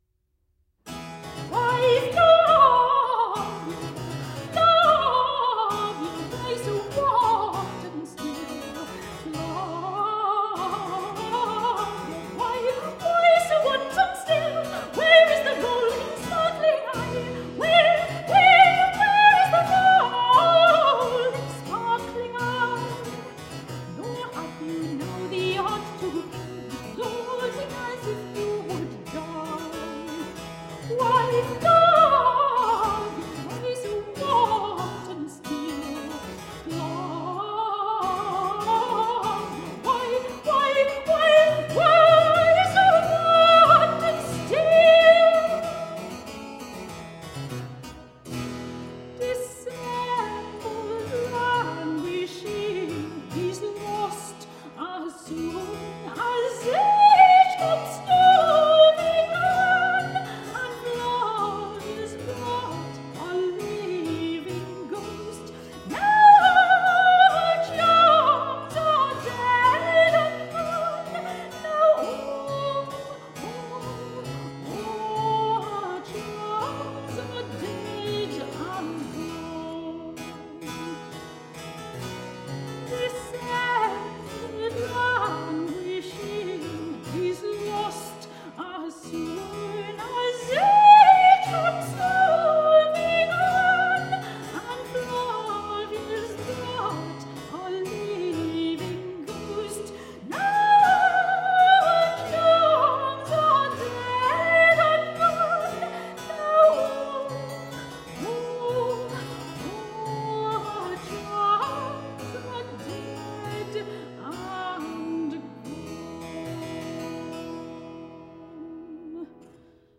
performed by the radiant clear soprano
Classical, Renaissance, Baroque, Classical Singing